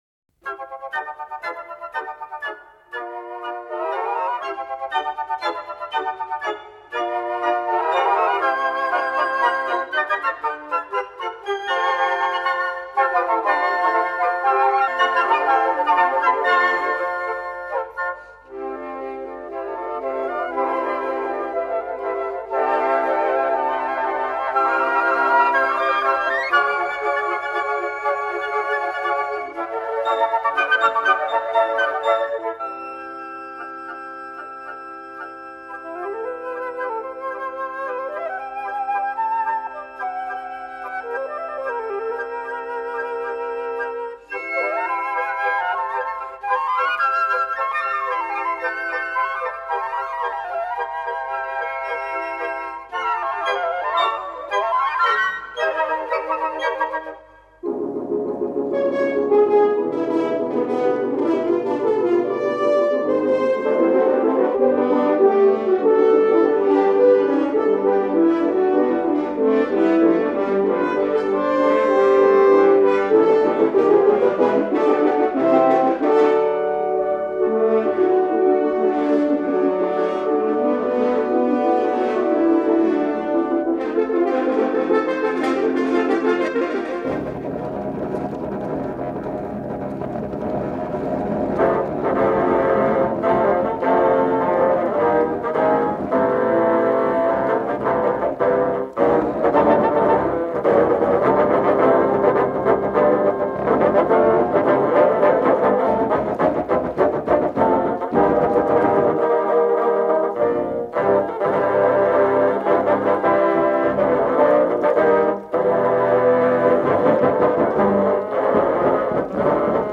Recorded in Vienna in 1984.
flute.
horn.
bassoon.
trumpet.
trombone